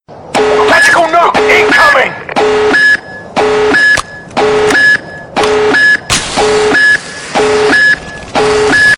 Incoming-Alert
Incoming-Alert.mp3